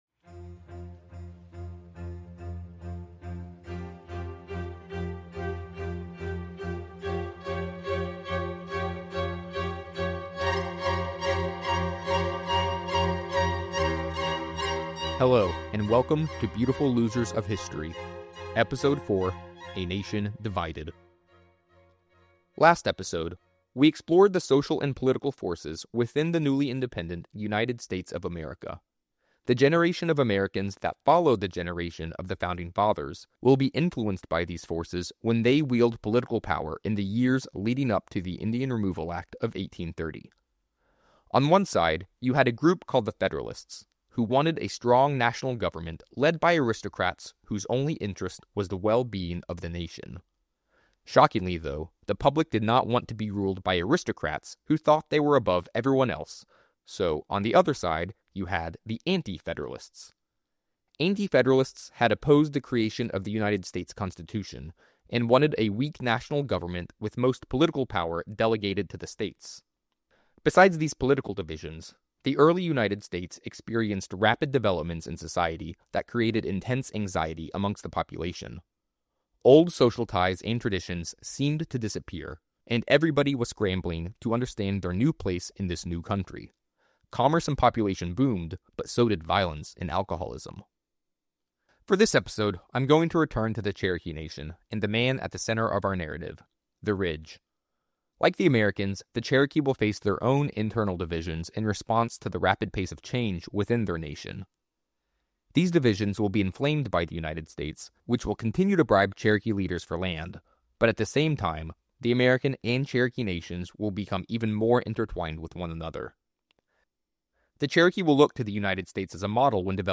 A narrative history podcast telling the stories of the people and movements that were on the right side of history, but ultimately lost.